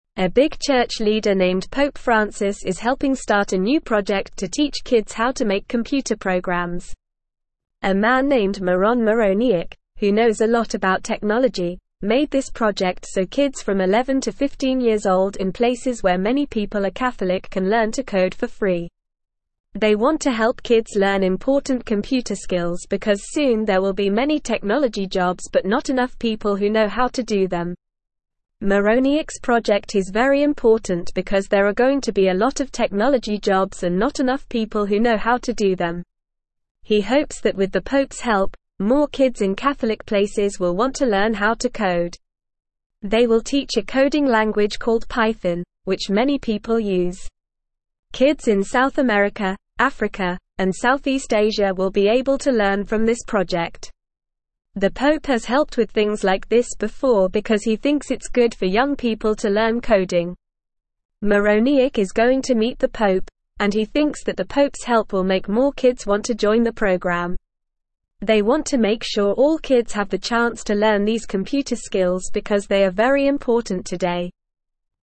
Normal
English-Newsroom-Lower-Intermediate-NORMAL-Reading-Church-Leader-Supports-Free-Computer-Class-for-Kids.mp3